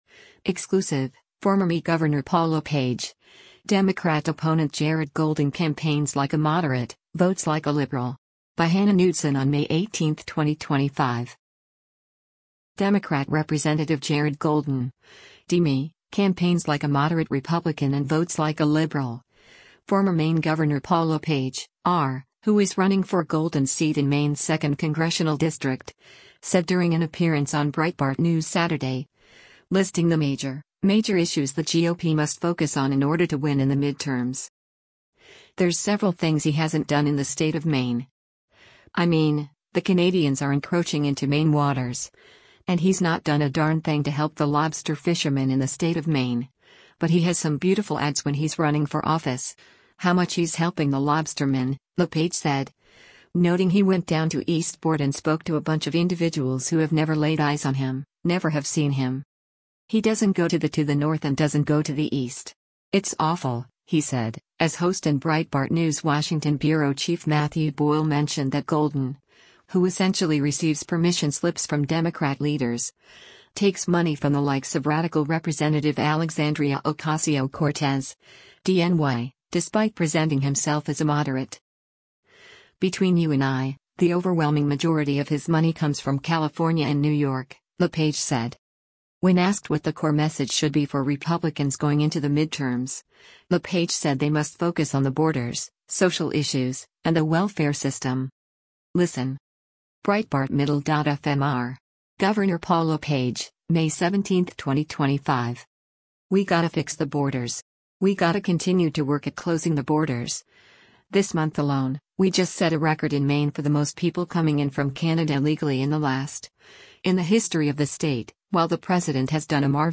Democrat Rep. Jared Golden (D-ME) “campaigns like a moderate Republican and votes like a liberal,” former Maine Gov. Paul LePage (R) — who is running for Golden’s seat in Maine’s Second Congressional District — said during an appearance on Breitbart News Saturday, listing the “major, major issues” the GOP must focus on in order to win in the midterms.
Breitbart News Saturday airs on SiriusXM Patriot 125 from 10:00 a.m. to 1:00 p.m. Eastern.